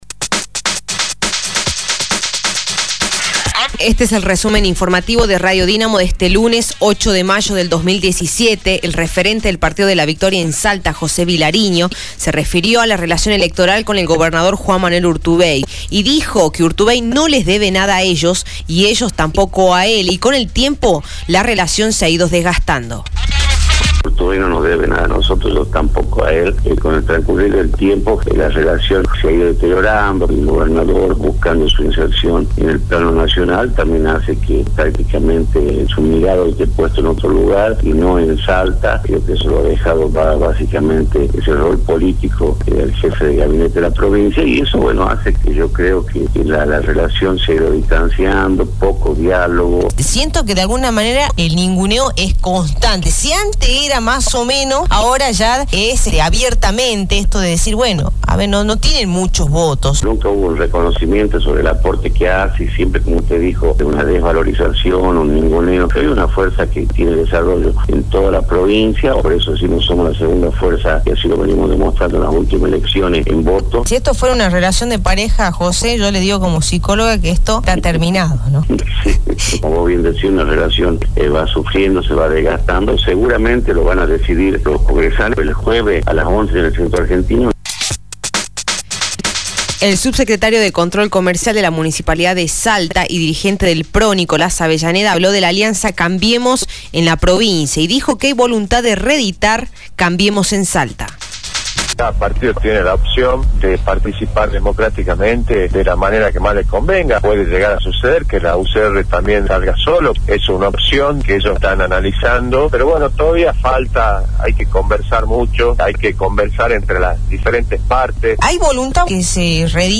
Resumen Informativo de Radio Dinamo del día 08/05/2017 1° Edición